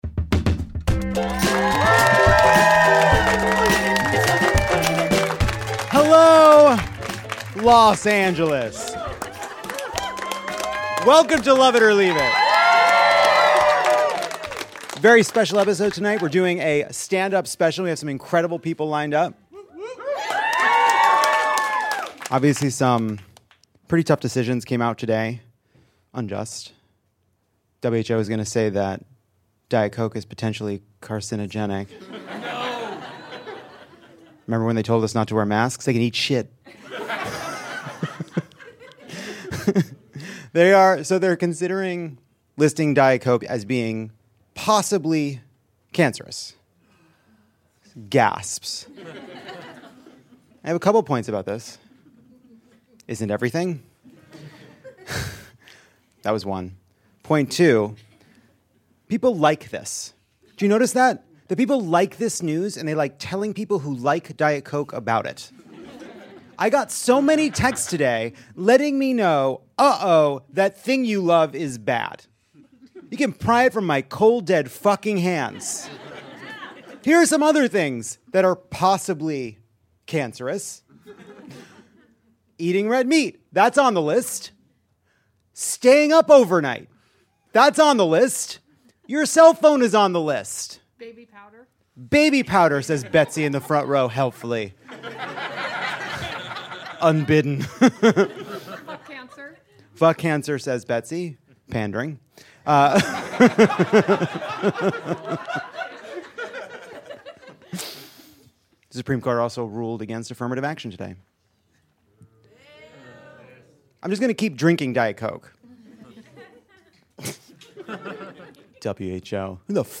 Lovett Or Leave It closes out this year’s Pride month with a fantastic stand up special. The legendary Paula Poundstone joins Jon as co-host and basically takes over, which rules.